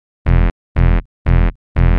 TSNRG2 Off Bass 022.wav